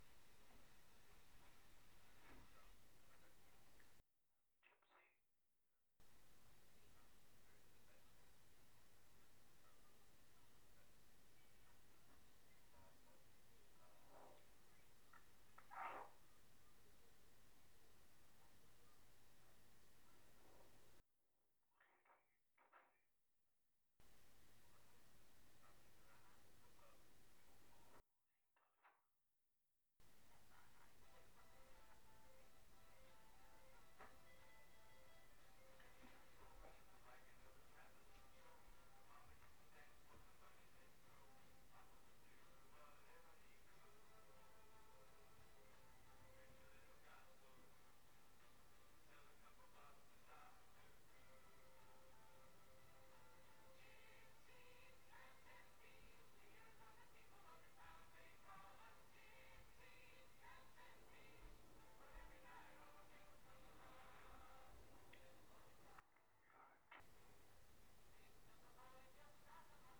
This was an ultrasound projection of a song (Gypsy) from an ultrasound speaker.
But the volume of the sound in the air can be controlled such that it is barely audible.
ultrasound audio projection
Hardly a sound yet it is still just barely present in the air.